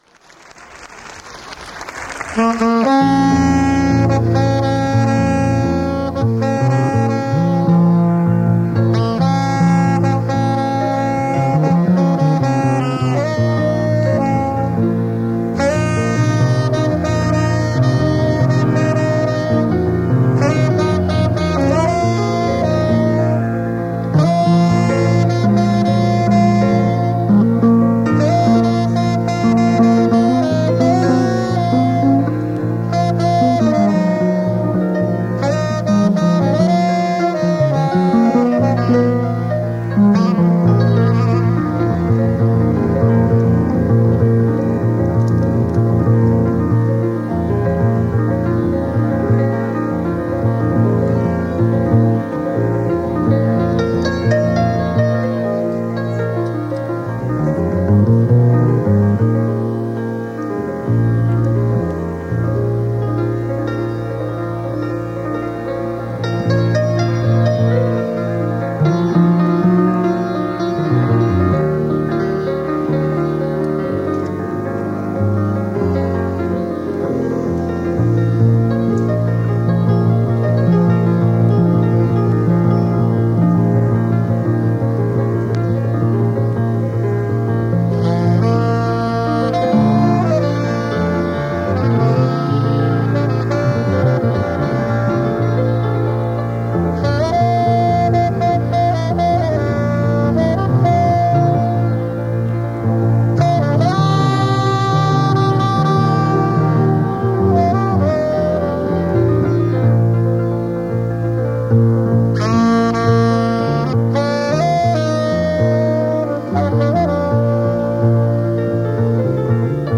piano
bass